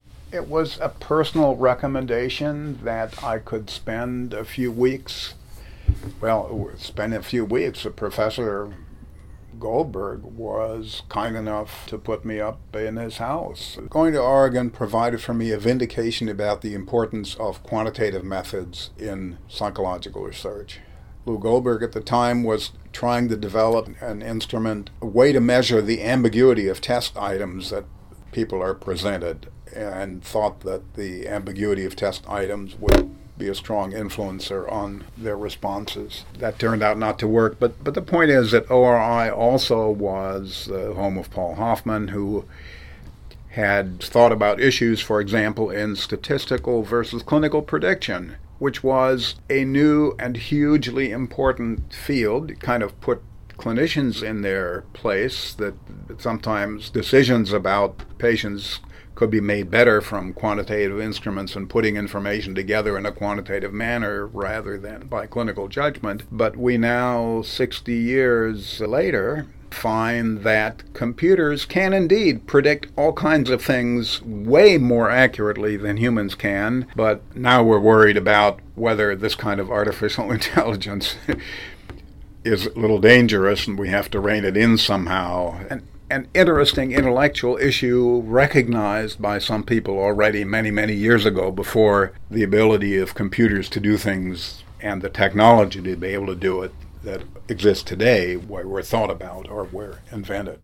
He describes that experience below: